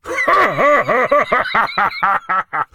G_laugh1.ogg